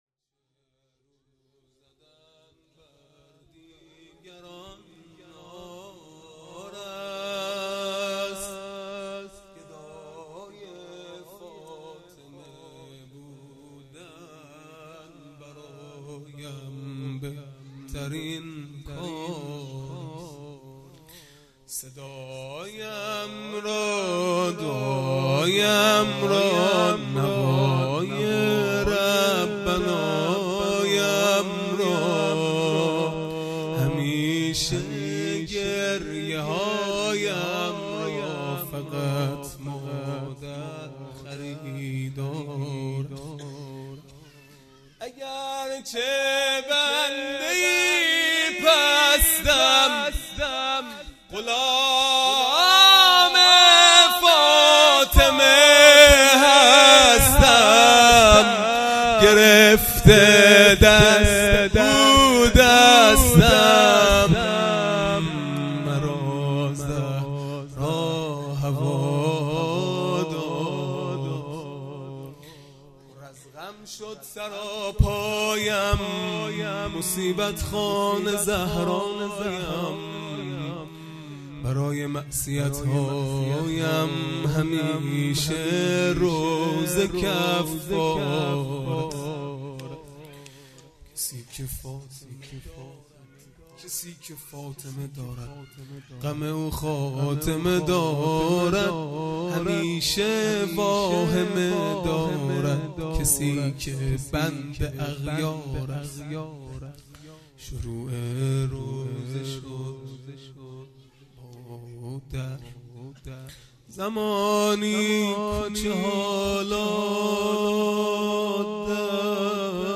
مناجات پایانی | در این عالم اگرچه رو زدن بر دیگران عار است
فاطمیه دوم(شب سوم) | به یاد شهدای فاطمیون | 9 بهمن ۱۳۹۸